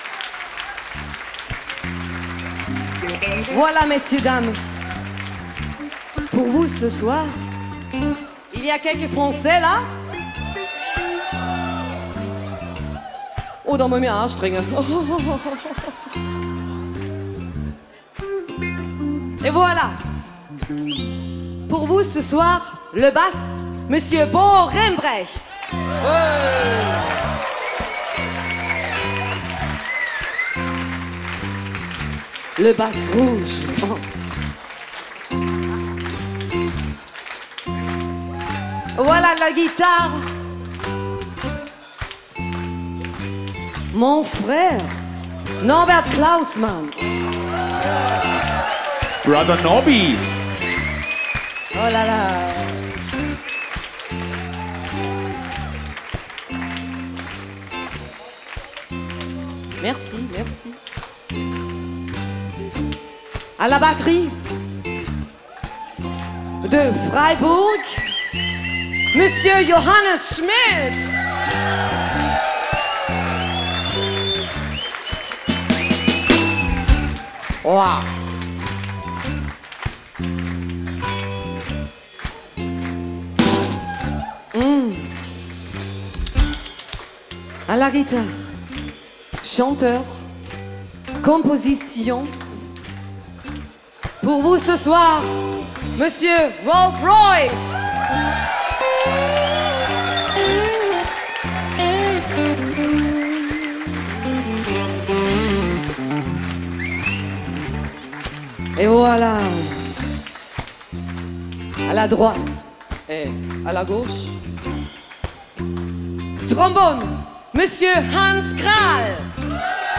Aktuelle Live Doppel-CD CD2